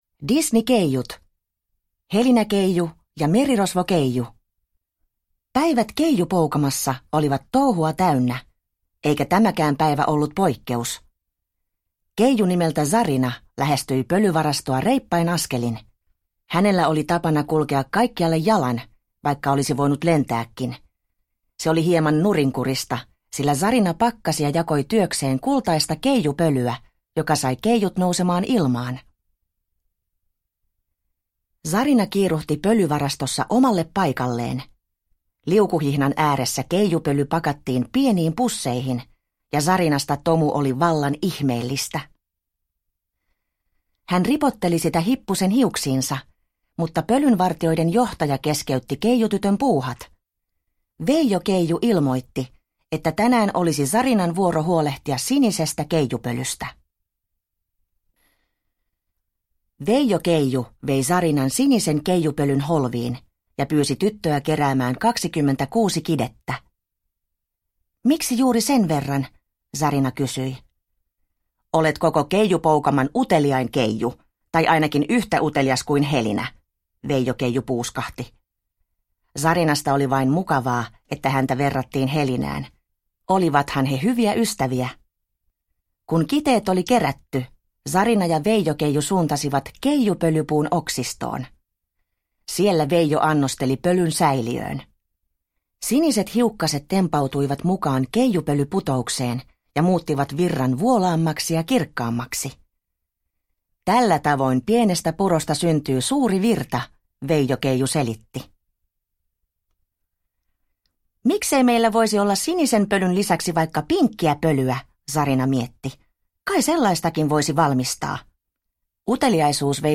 Helinä-keiju ja merirosvokeiju – Ljudbok – Laddas ner